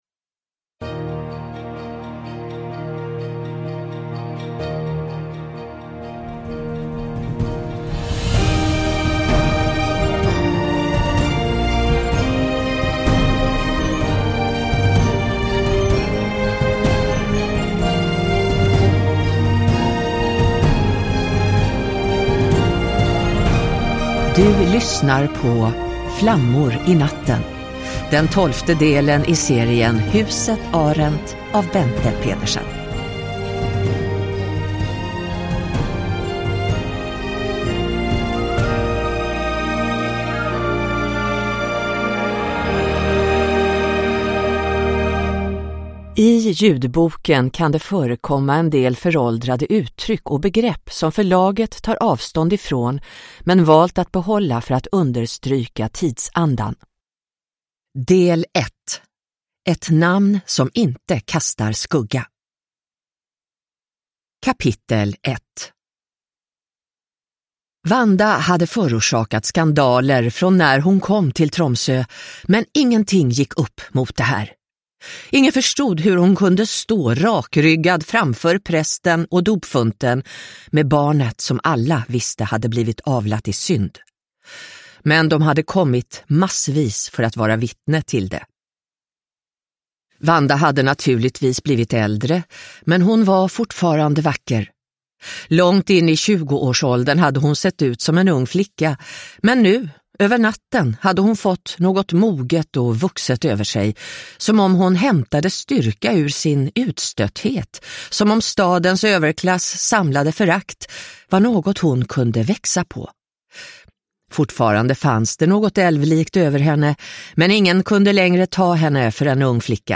Flammor i natten – Ljudbok – Laddas ner